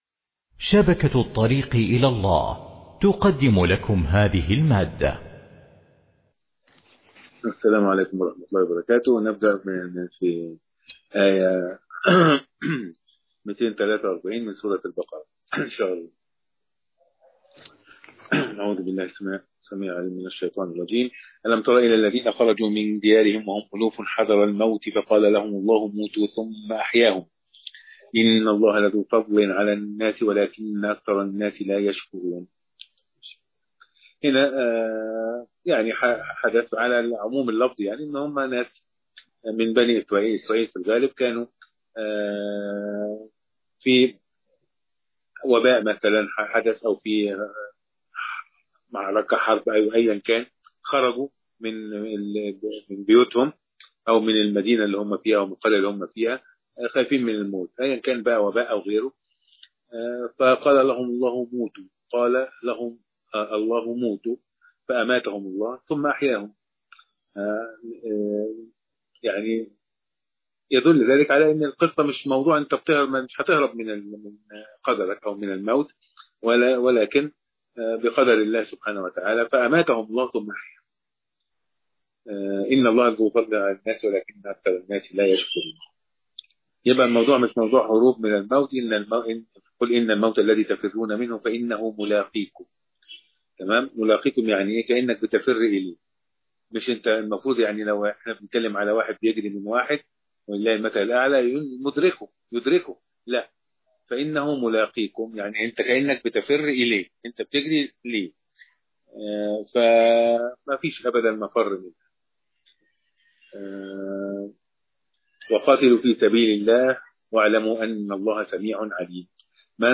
مقرأة